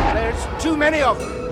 When TIE fighters began to attack the Rebel starfighters in space, Telsij cried out that they were being overwhelmed.